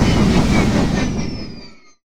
stormimpact.wav